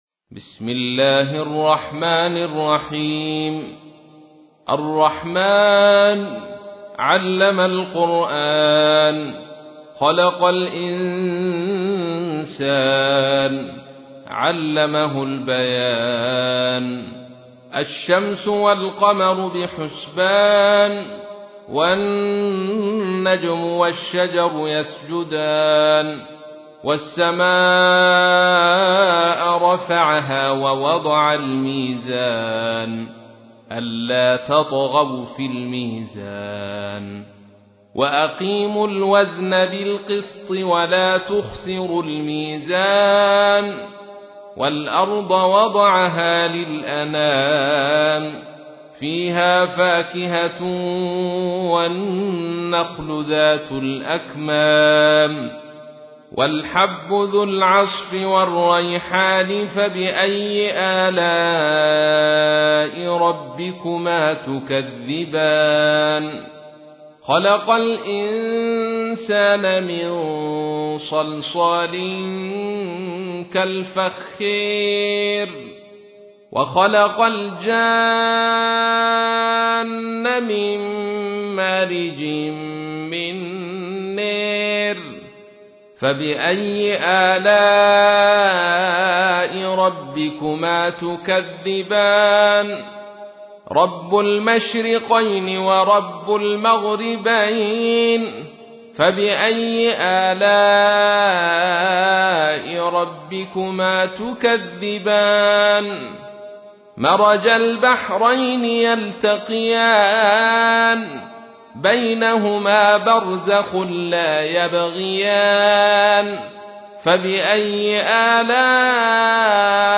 تحميل : 55. سورة الرحمن / القارئ عبد الرشيد صوفي / القرآن الكريم / موقع يا حسين